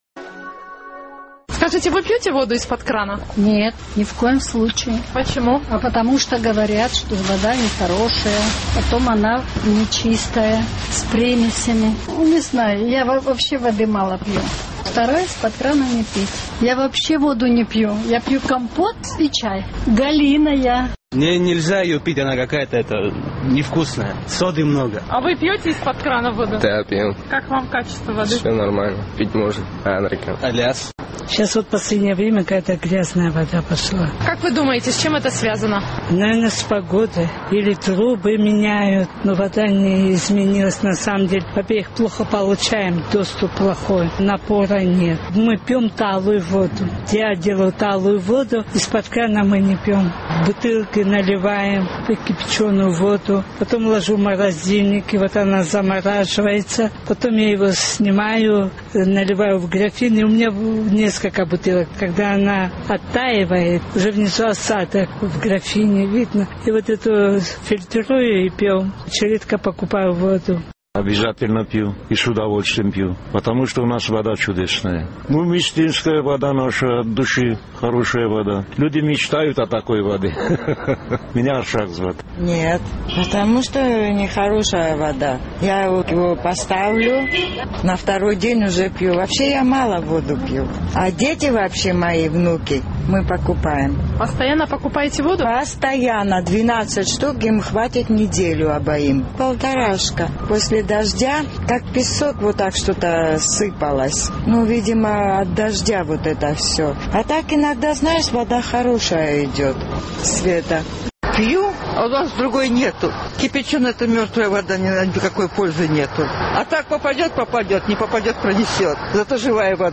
Наш корреспондент интересовалась на улицах Сухума, используют ли жители абхазской столицы в качестве питьевой воду из-под крана и с чем, по их мнению, может быть связано ухудшение ее качества.